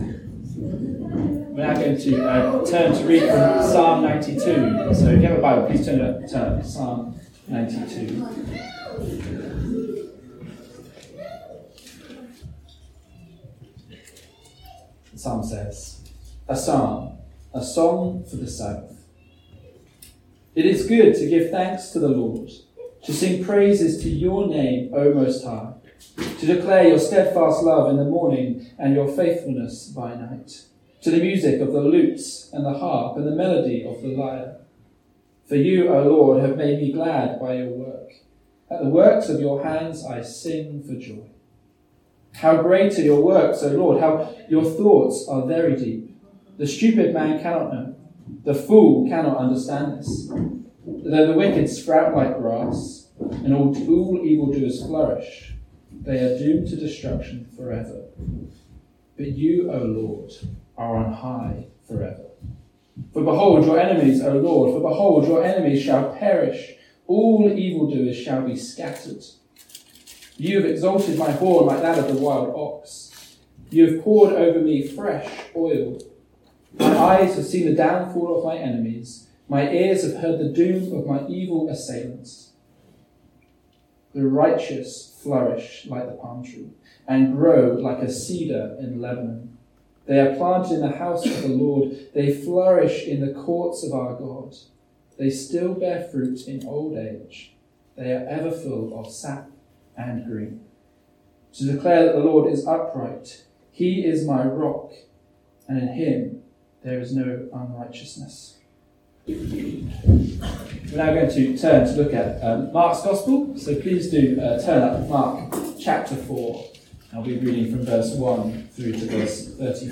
A link to the video recording of the 11:00am service, and an audio recording of the sermon.